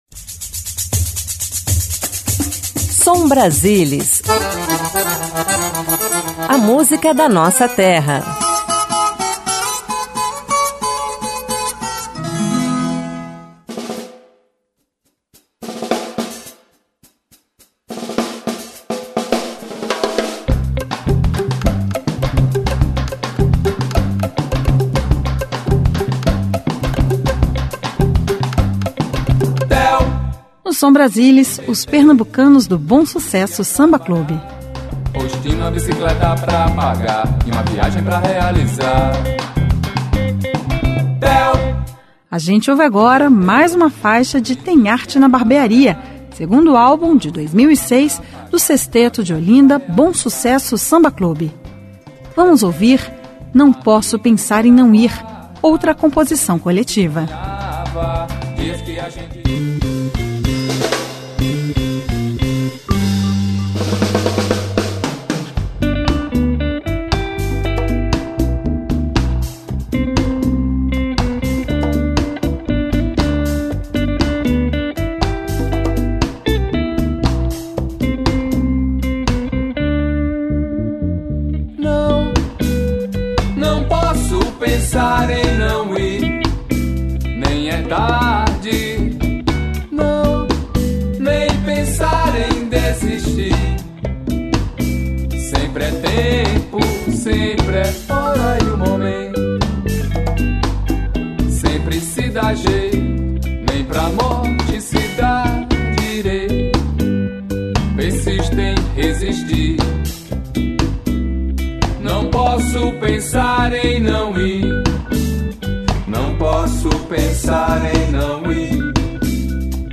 Samba
Ritmos pernambucanos
Ao vivo